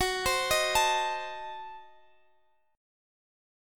Listen to Gbdim7 strummed